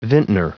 Prononciation du mot vintner en anglais (fichier audio)